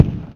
kick 15.wav